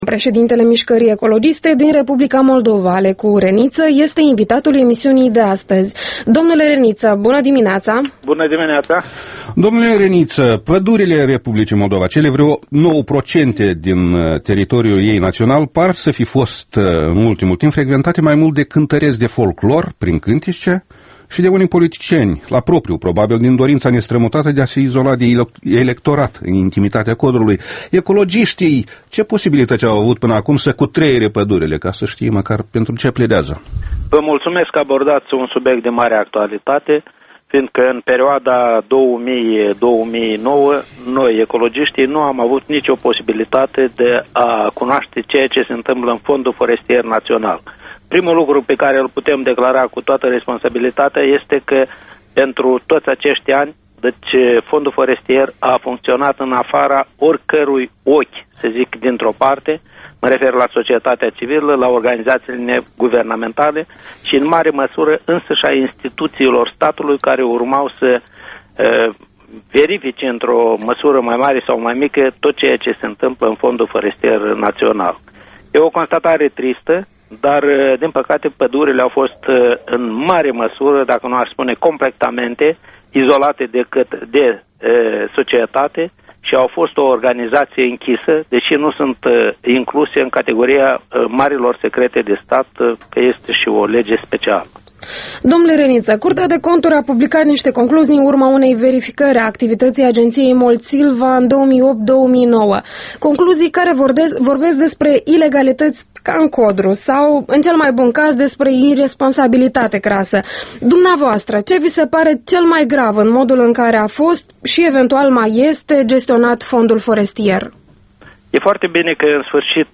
Interviul matinal EL: cu Alecu Reniță